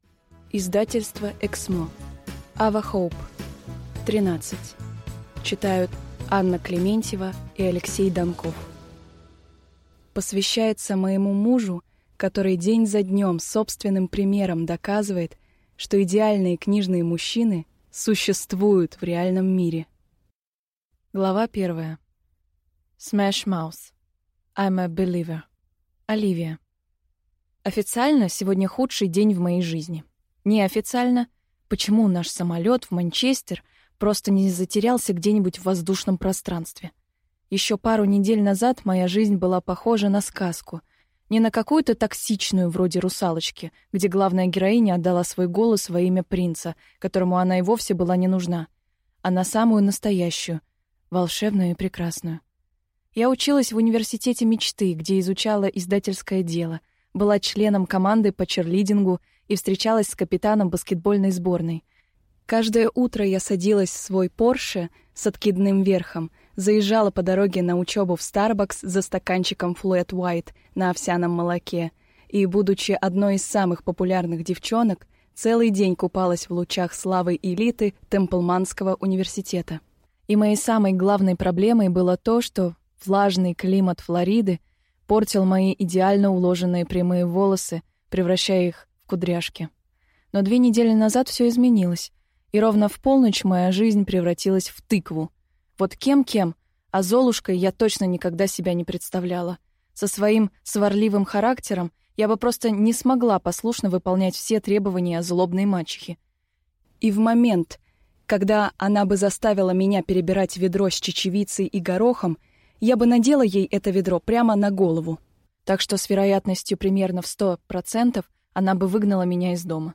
ВЕСЬ ЛАБКОВСКИЙ в одной книге. Хочу и буду. Люблю и понимаю. Привет из детства (слушать аудиокнигу бесплатно) - автор Михаил Лабковский